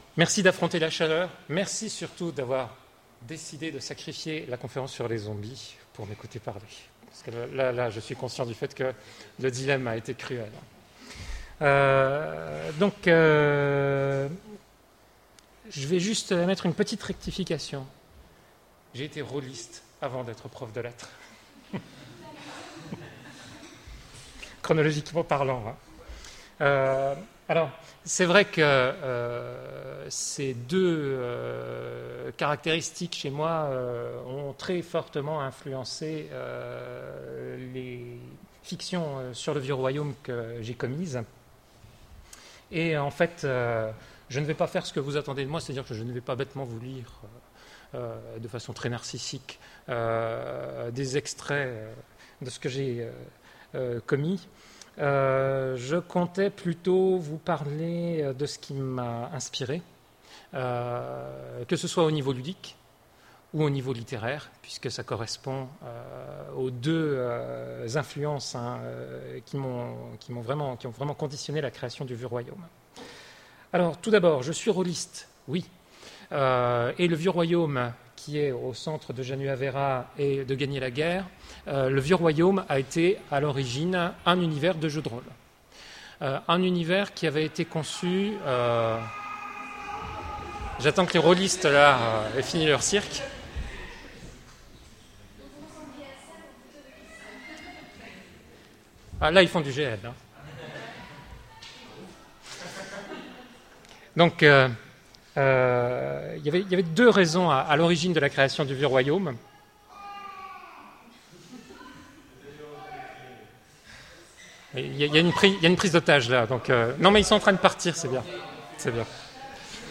Conférence-lecture Jean-Philippe Jaworski
A l'occasion du festival l'Ecrit de la fée à Dijon, Jean-Philippe Jaworski a fait une conférence sur Gagner la guerre et lu des extraits... Attention, les conditions d’enregistrement n’étant pas optimum, le son ne l’est pas non plus, avec des variations de niveau.